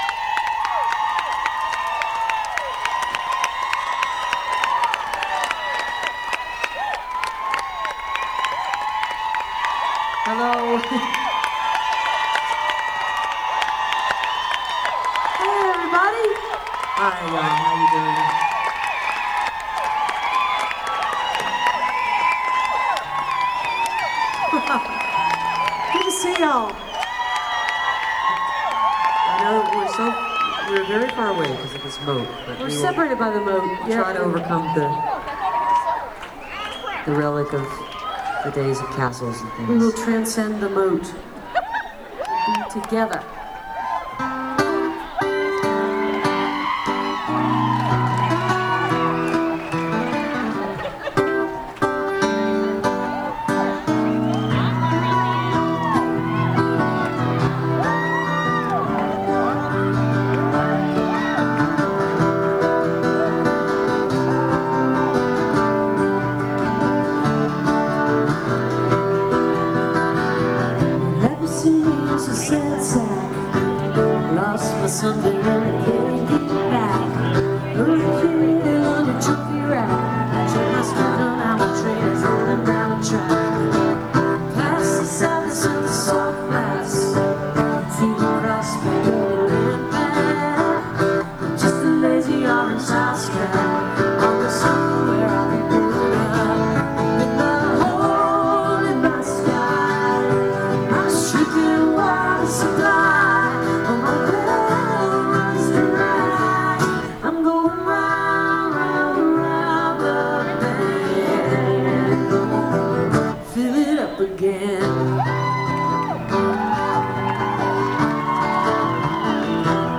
(acoustic show)